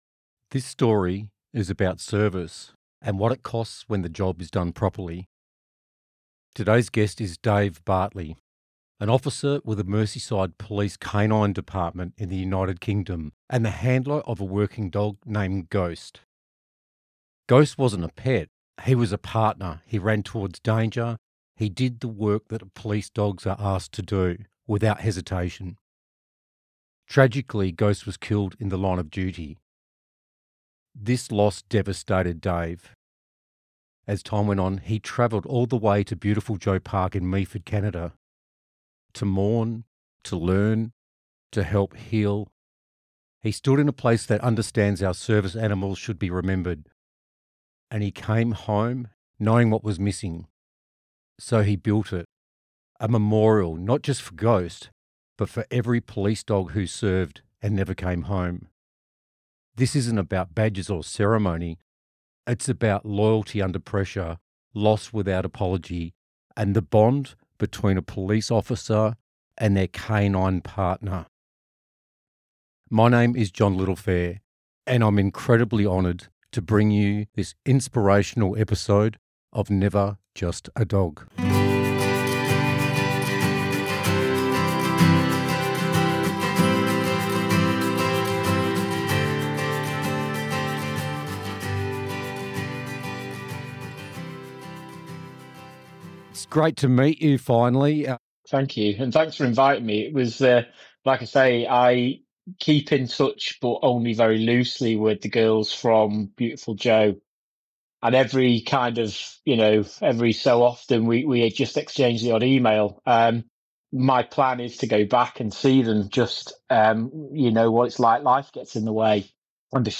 This conversation explores service, loyal